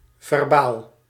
Ääntäminen
US : IPA : /ˈvɝ.bəl/ RP : IPA : /ˈvɜː.bəl/